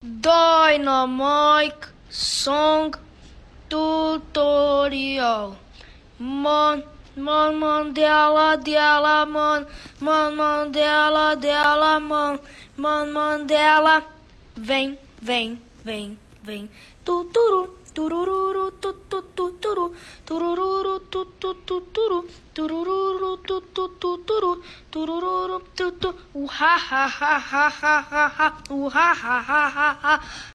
детский голос